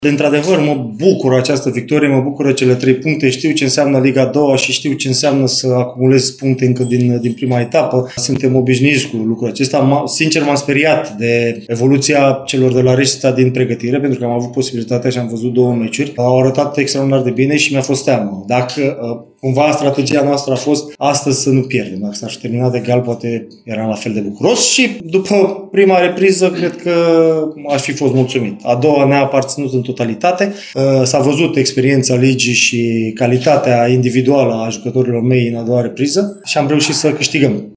Tehnicianul Sloboziei, Adrian Mihalcea, admite că s-a temut de întâlnirea de la Reșița, după ce i-a văzut pe bănățeni în două meciuri de verificare: